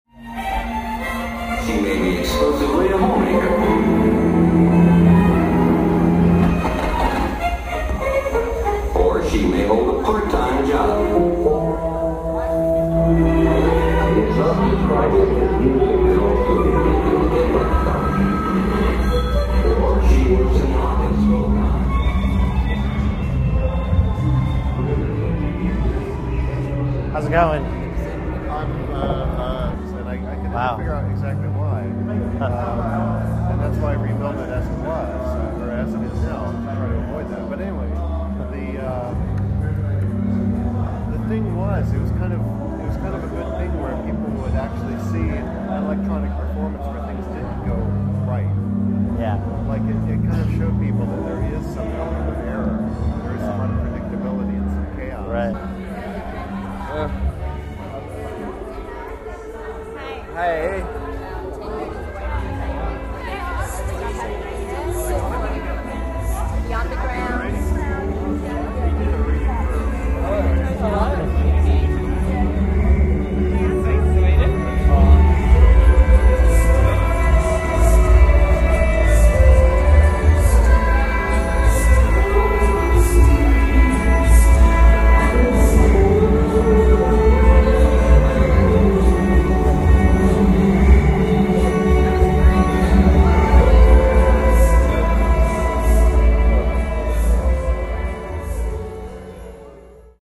Live at Electrofringe 2001 - September 28, 2001, Mission Theatre, Newcastle
plu-chatter.mp3